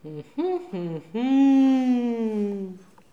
Les sons ont été découpés en morceaux exploitables. 2017-04-10 17:58:57 +02:00 540 KiB Raw Permalink History
hum-professoral_02.wav